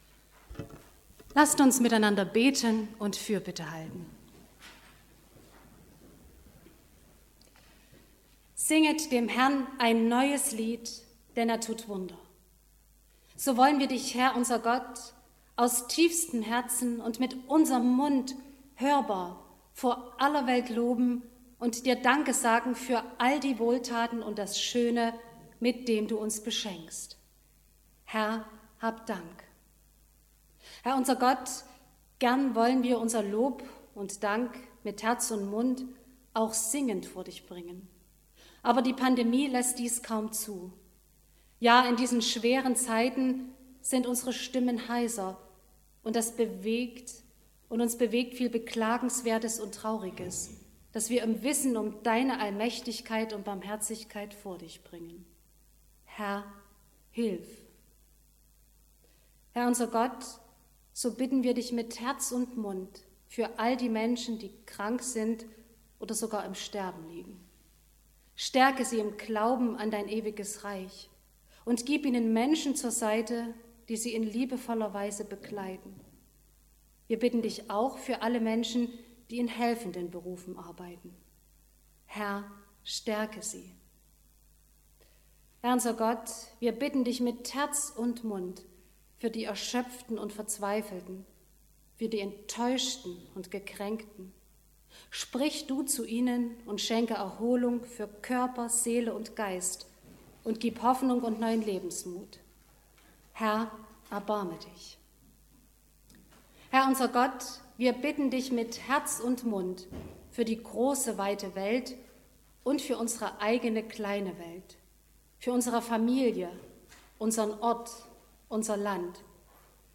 Gottesdienst vom Sonntag Kantate nachhören
00_-_Desktop_-_Kantate_Gebet___Segen.mp3